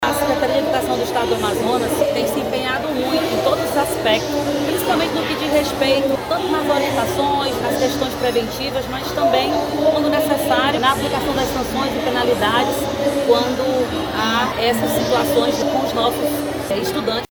As ações incluem palestras, rodas de conversa e materiais educativos, com o objetivo de transformar o ambiente escolar em um espaço seguro e acolhedor, como explica a secretária de Educação, Arlete Mendonça.